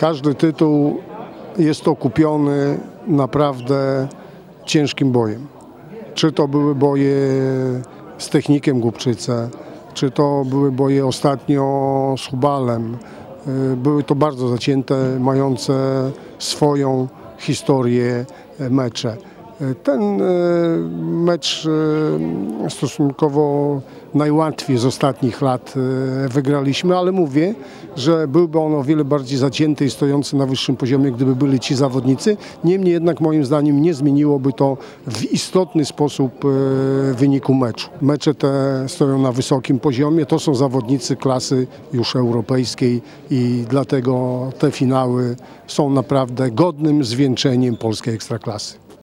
– Każdy tytuł okupiony jest bardzo ciężką pracą – powiedział tuż po finałowym spotkaniu